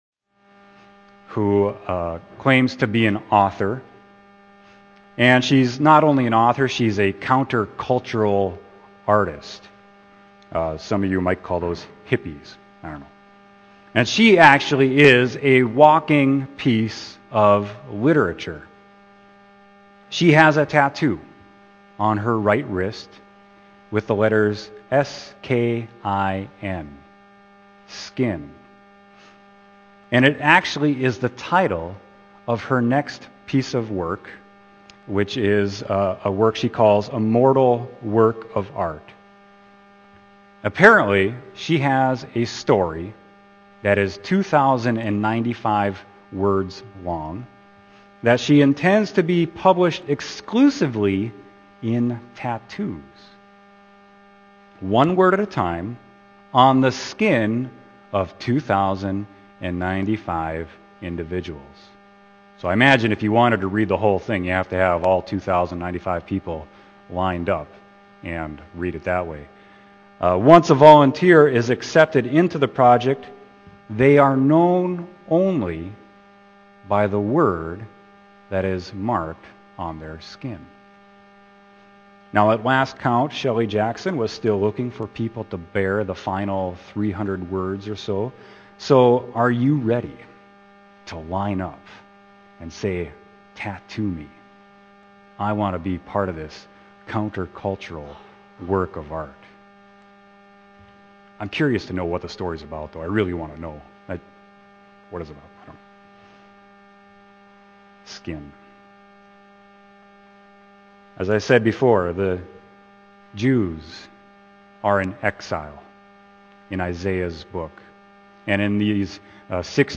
Sermon: Isaiah 44.1-5